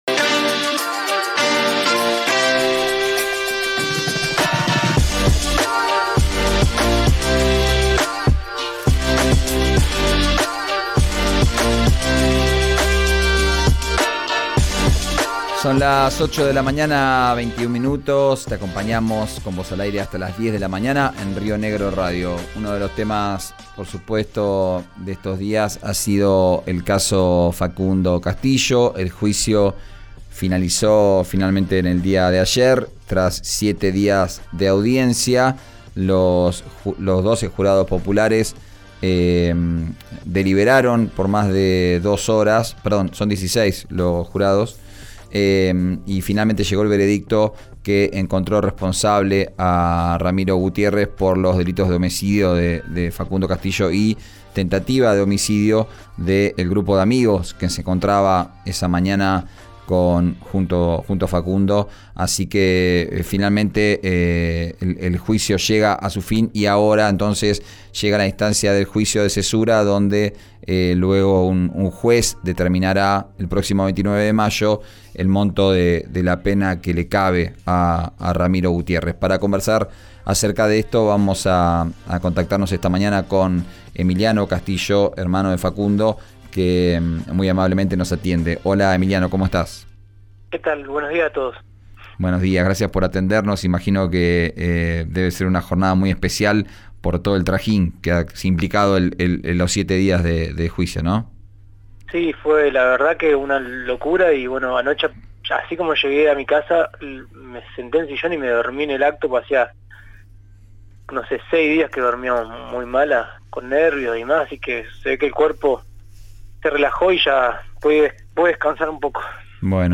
en «Vos al Aire», por RÍO NEGRO RADIO: